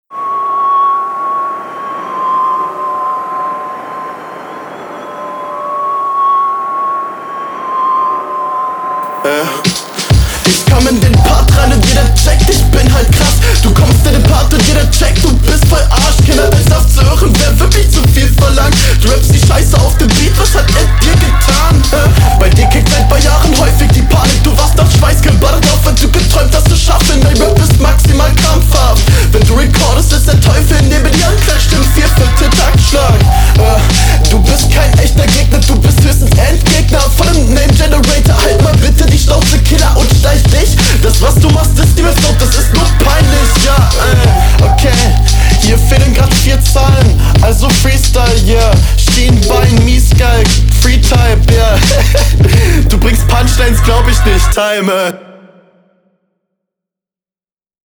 Feier die Art wie du Rappst